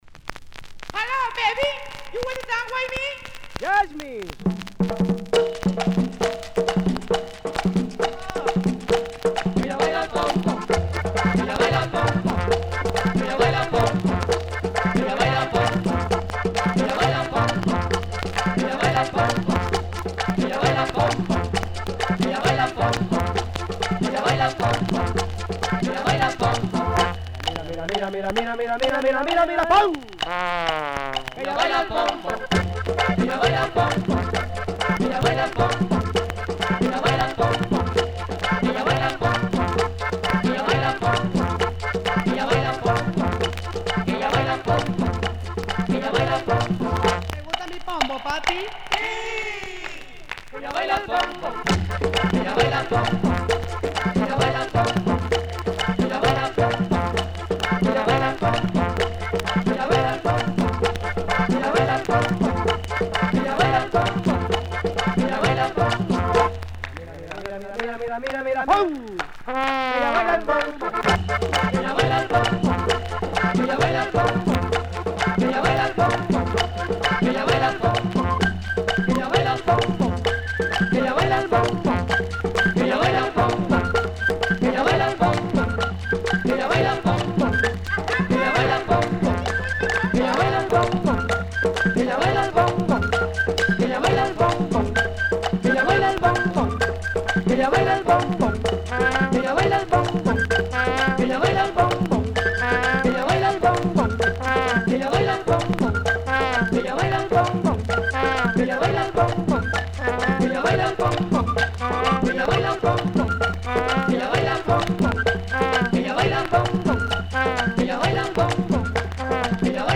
flipside is also a cover
Cumbia